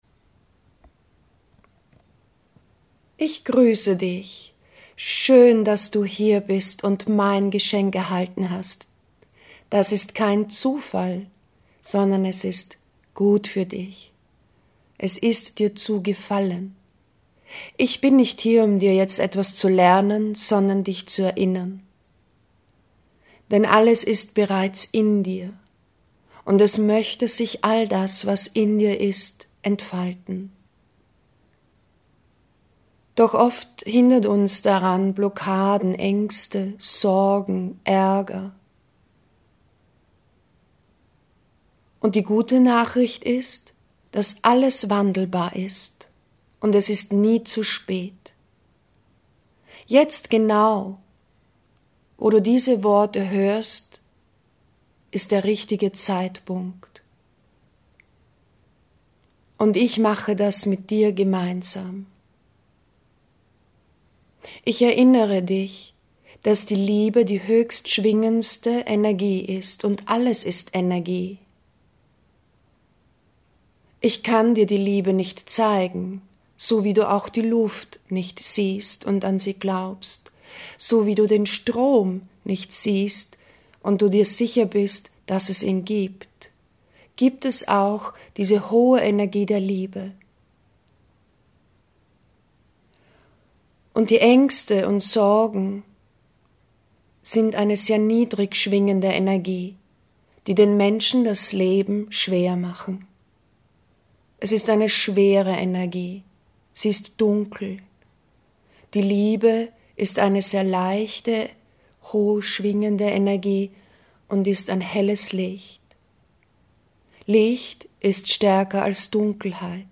Danke für Deine Eintragung! Hier ist Deine Meditations MP3!
Angst-in-Liebe-wandeln-Meditation-Geschenk-1.mp3